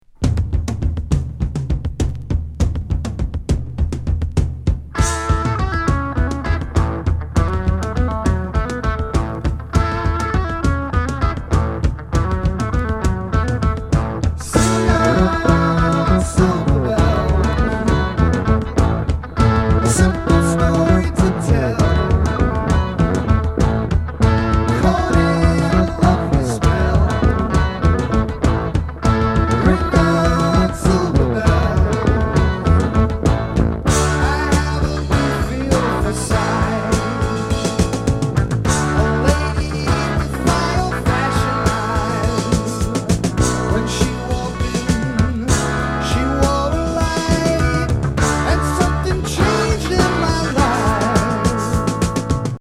NY録音作。ズンドコ・ファンキー・ロック